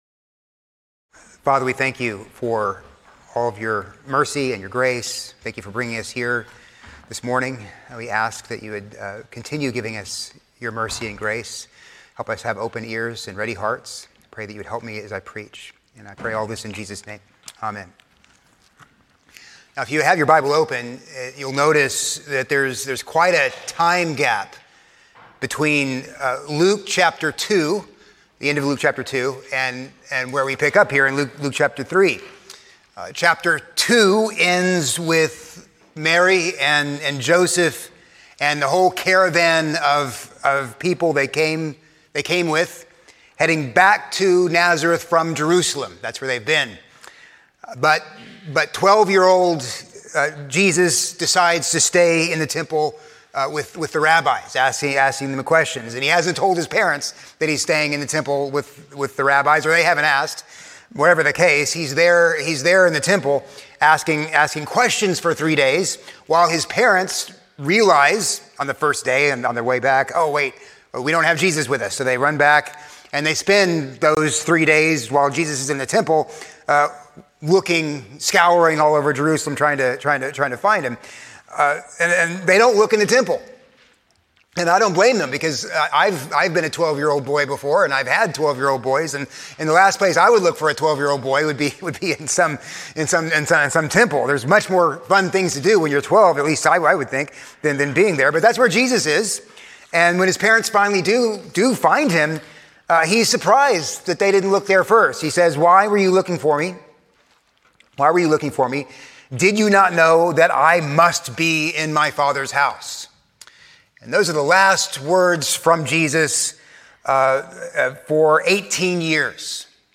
A sermon on Luke 3:1-6